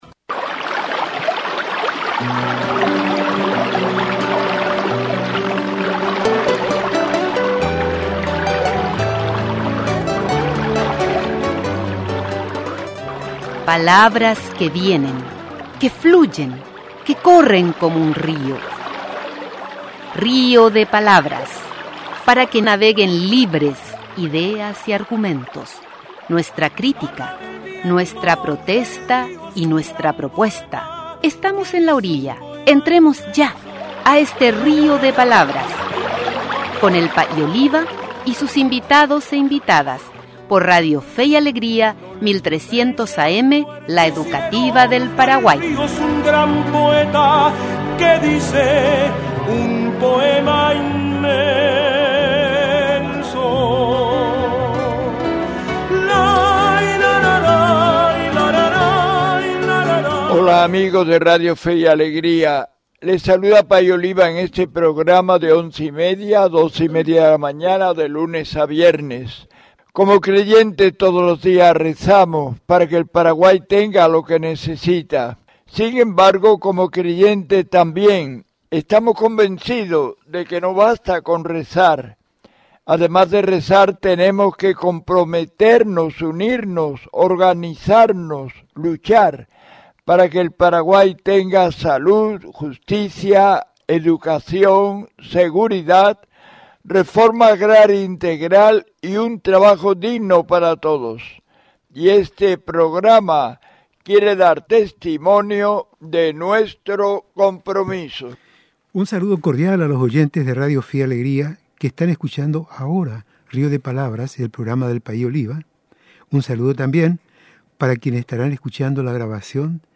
Luego se comprometió con los pueblos indígenas de Argentina y Paraguay, elaborando una visión teológica que incorpora la sabiduría indígena y su vinculación con la naturaleza. Descargue y escuche la entrevista.